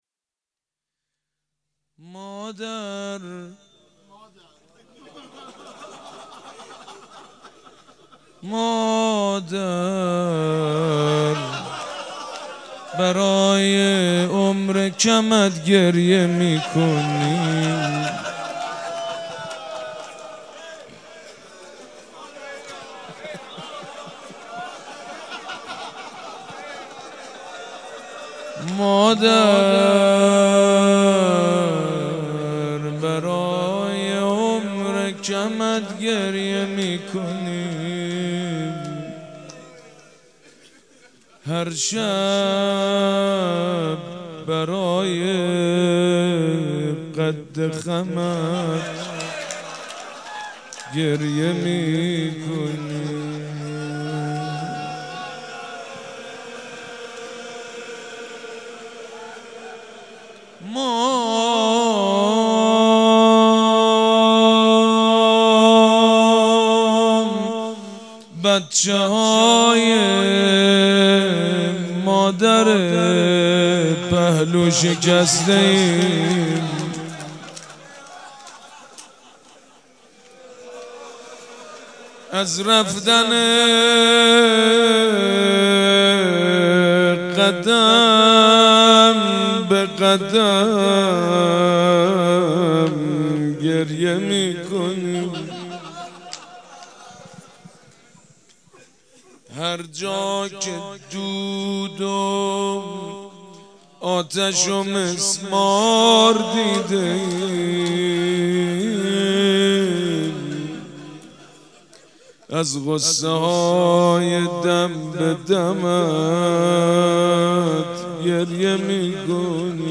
شب دوم فاطميه دوم١٣٩٤
روضه
مراسم عزاداری شب دوم